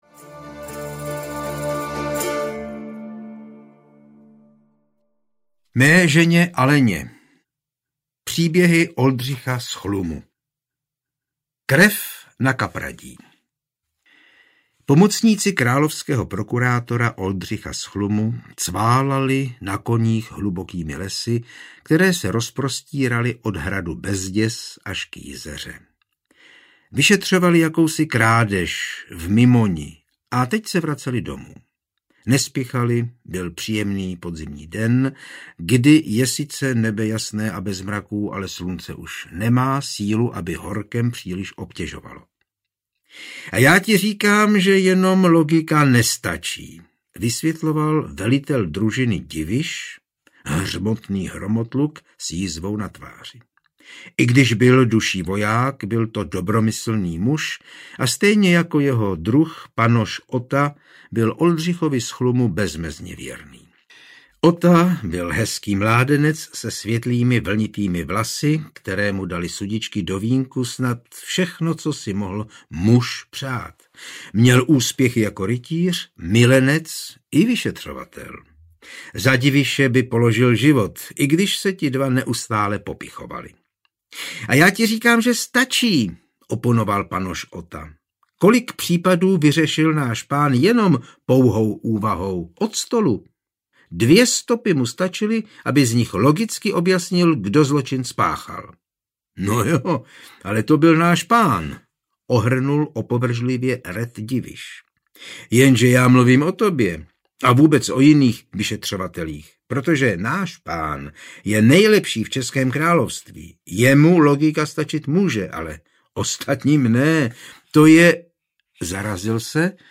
Krev na kapradí audiokniha
Ukázka z knihy